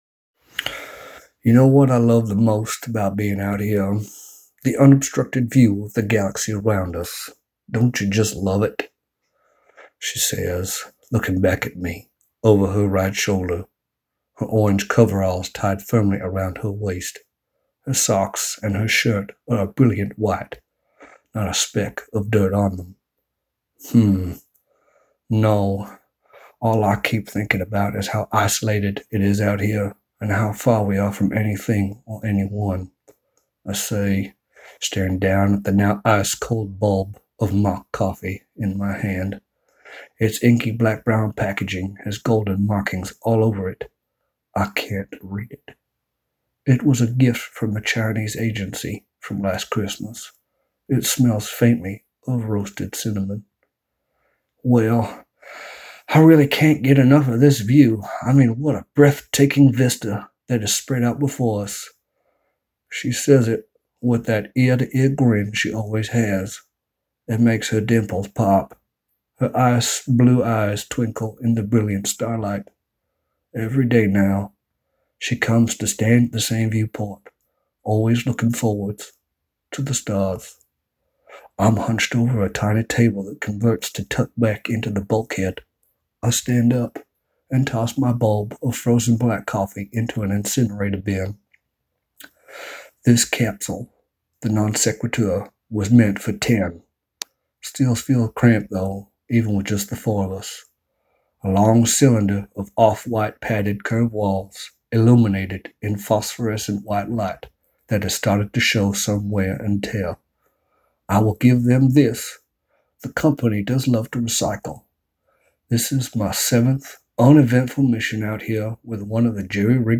And NOW for shits and giggles you can listen to me narrate Chapter One .